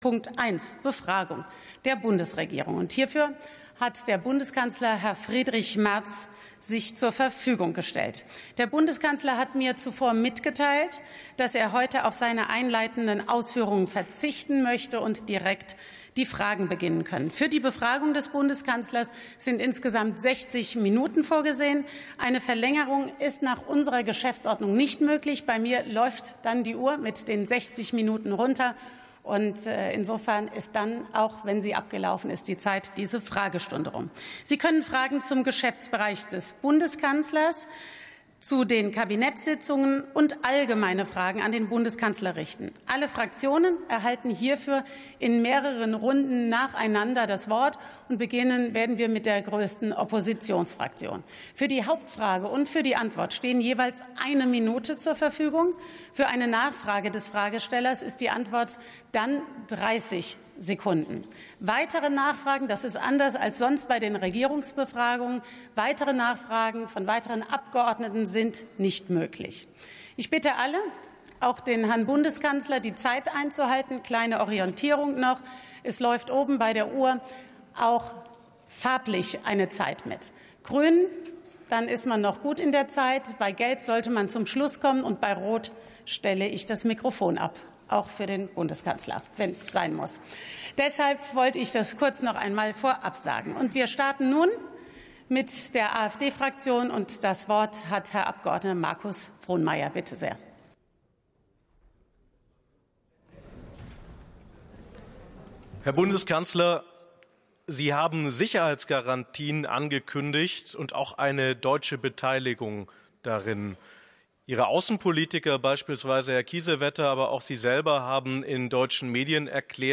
49. Sitzung vom 17.12.2025. TOP 1: Befragung der Bundesregierung (Bundeskanzler) ~ Plenarsitzungen - Audio Podcasts Podcast